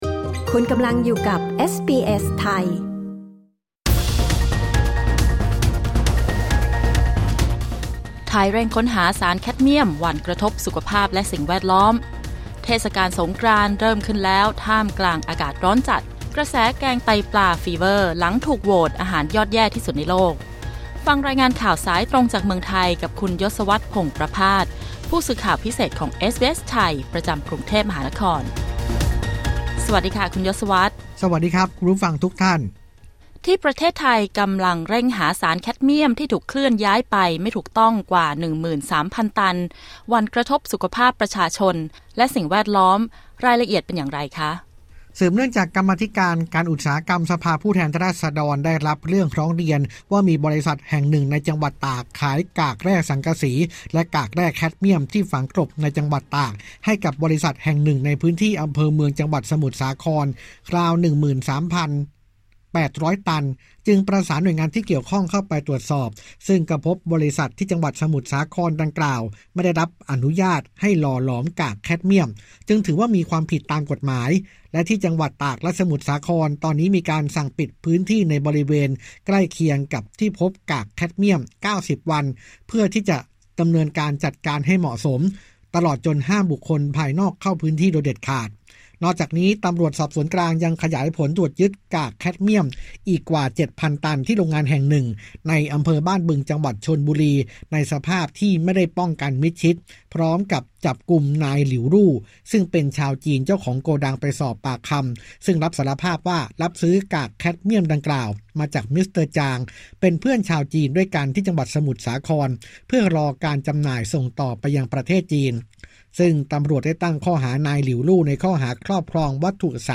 กด ▶ ฟังรายงานข่าวด้านบน